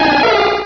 Cri de Capumain dans Pokémon Rubis et Saphir.